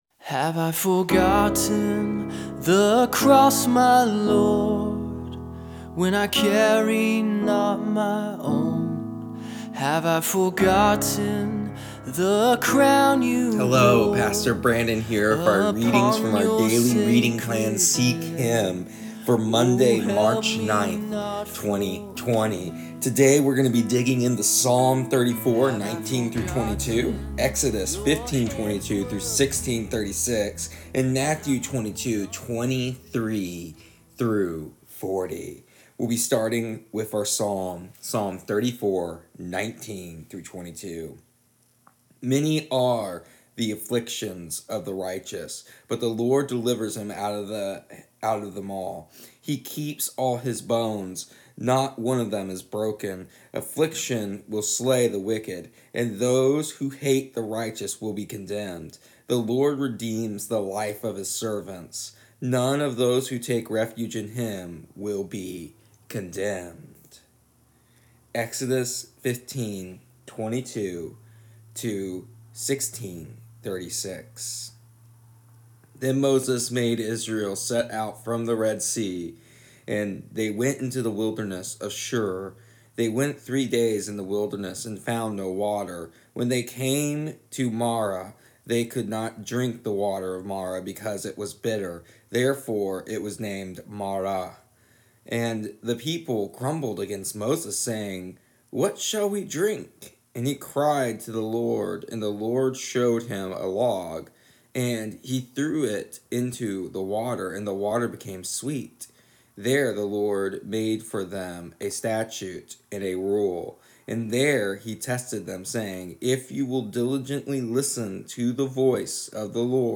Here are our daily readings and audio devotional for March 9th, 2020.